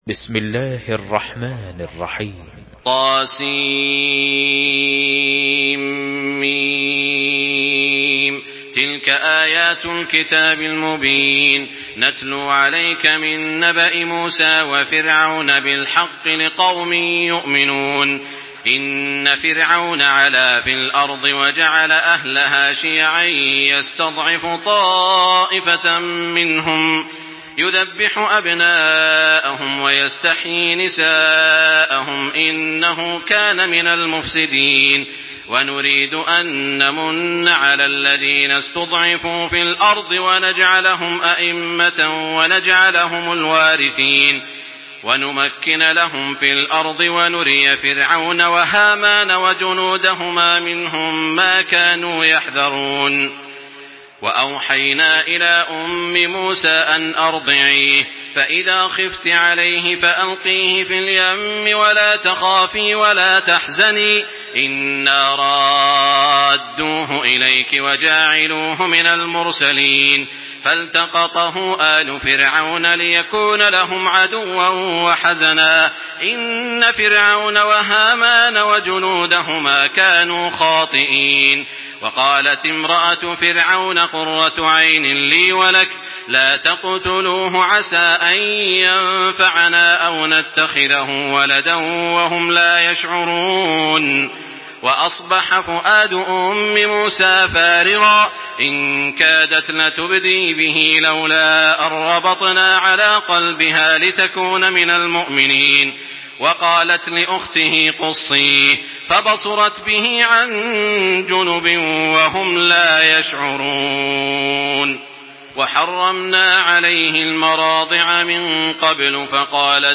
Surah Al-Qasas MP3 by Makkah Taraweeh 1425 in Hafs An Asim narration.
Murattal Hafs An Asim